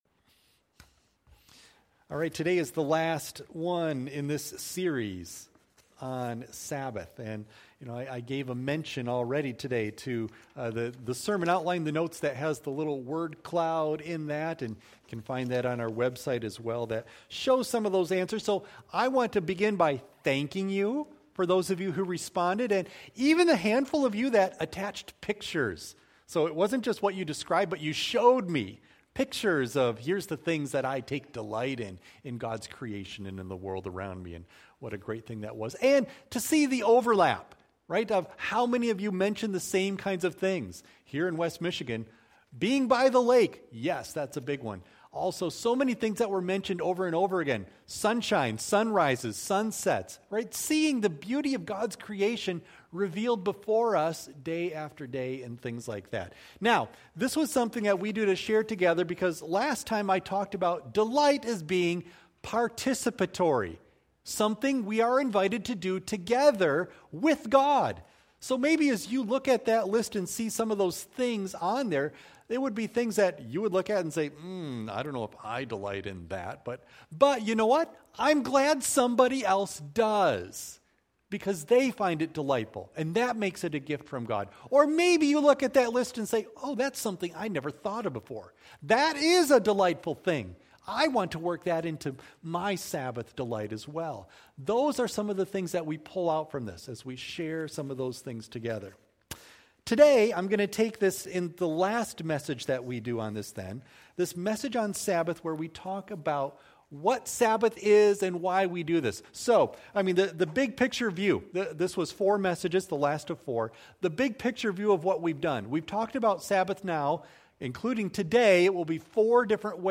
Audio of Message